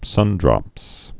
(sŭndrŏps)